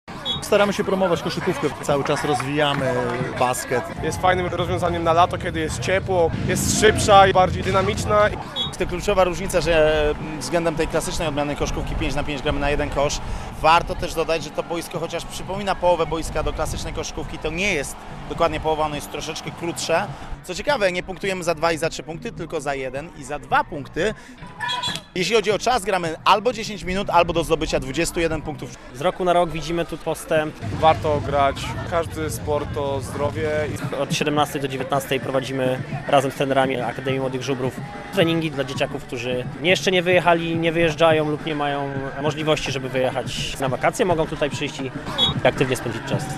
Koszykówka 3x3 propozycją na aktywne lato w mieście - relacja